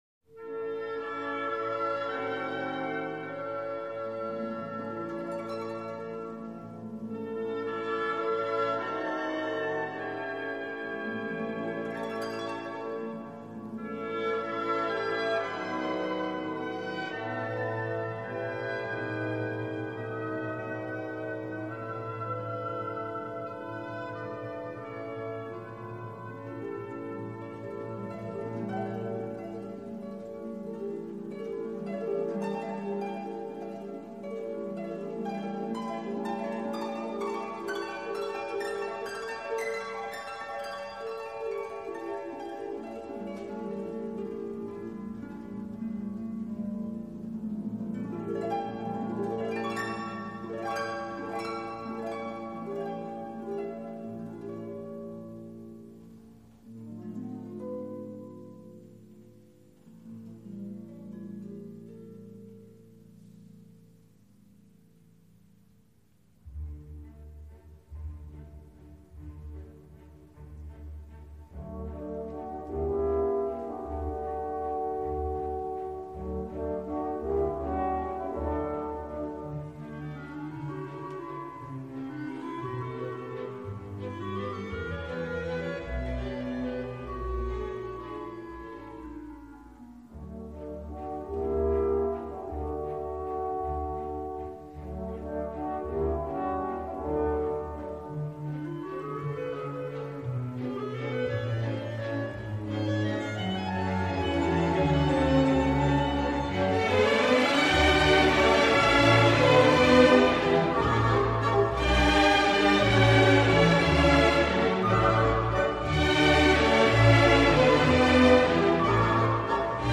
Щелкунчик - Чайковский П.И. Классическая музыка величайшего композитора для взрослых и детей.